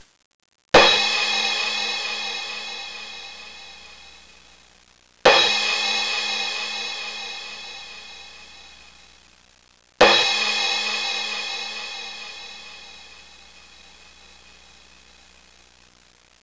Cymbals
Cymbals.wav